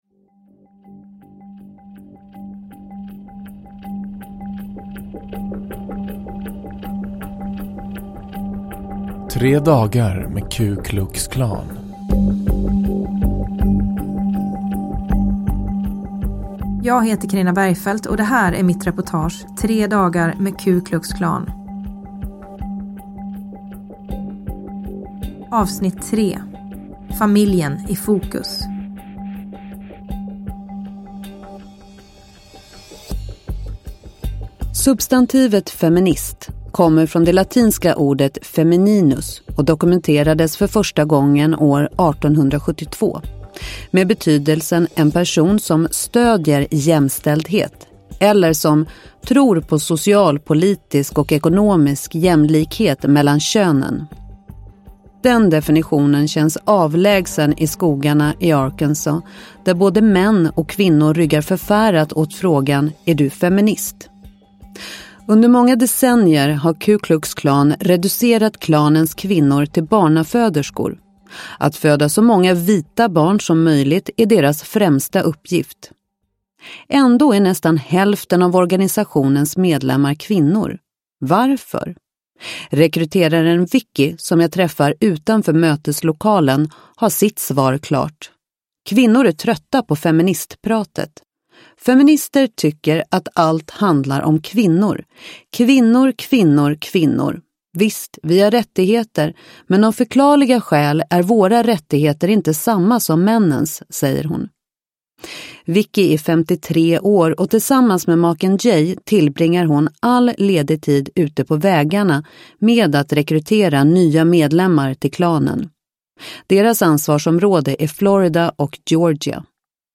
Bergfeldts Amerika. S2A3, Tre dagar med Ku Klux Klan – Ljudbok – Laddas ner